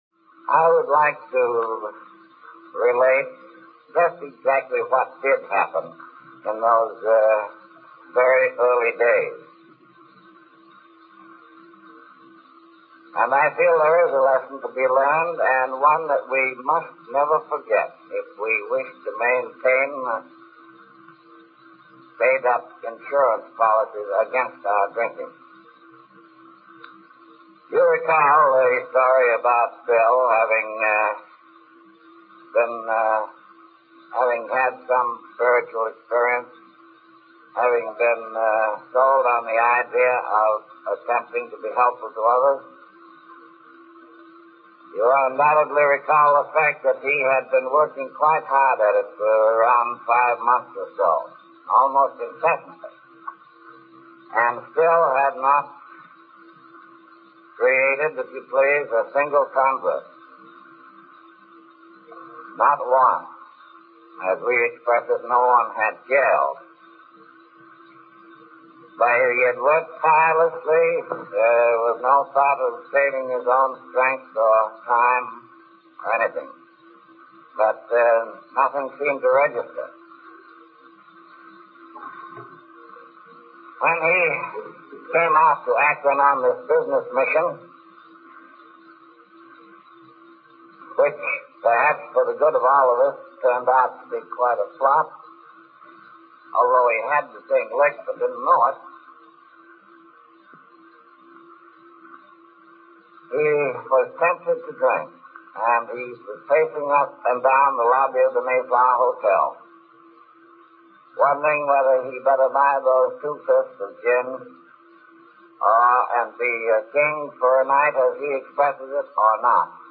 To give some background on how this movement got started, and why June 10th is a significant date in the history of Alcoholics Anonymous, I ran across a talk by Dr. Bob Smith given in the late 1940s on the history of AA.
Sadly, the sound is pretty rough.
This talk, which goes over an hour, was filled with speed and pitch problems. So I have included a 6 minute excerpt which gives the gist of AA’s story as told by one of its founders.
Here is that talk by Dr. Bob Smith from, it’s believed, the late 1940s.
Dr.Bob-Smith-Early-Days-of-AA-speed-corrected.mp3